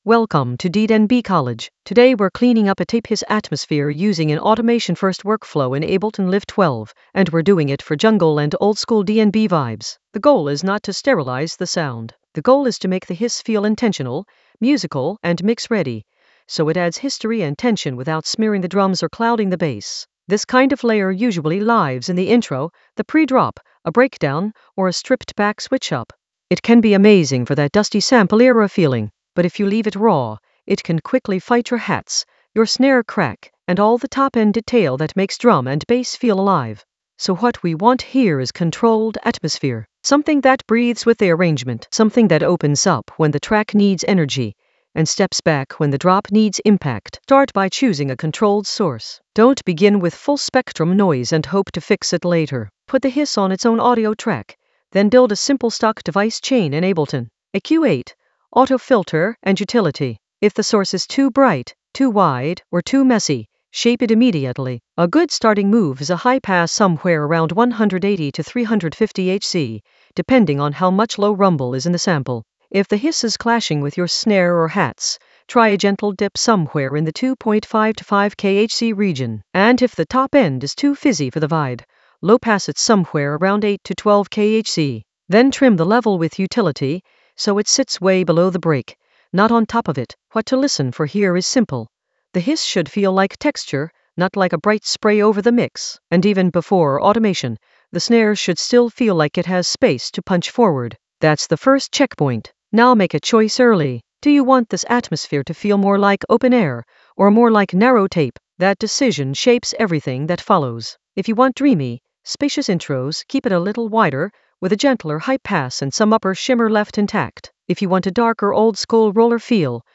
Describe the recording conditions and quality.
The voice track includes the tutorial plus extra teacher commentary.